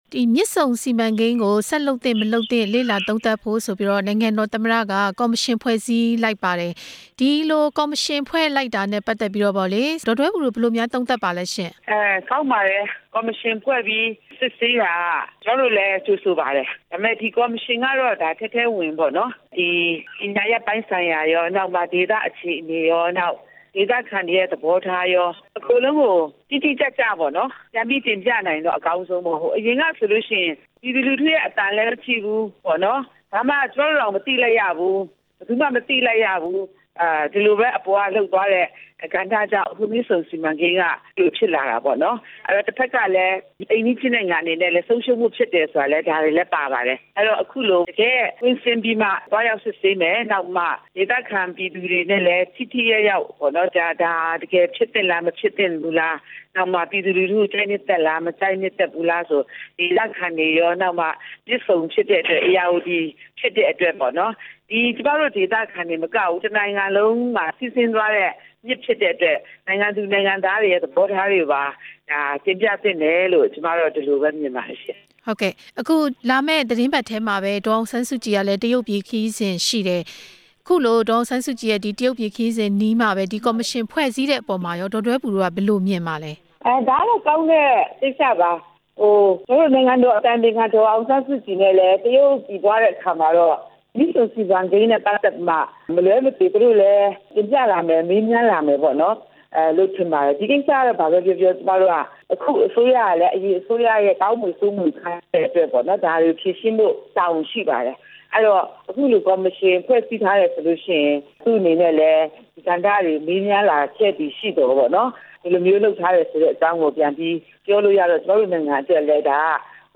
မြစ်ဆုံစီမံကိန်းပြန်စသင့်၊ မသင့် ဒေါ်ဒွဲဘူနဲ့ မေးမြန်းချက်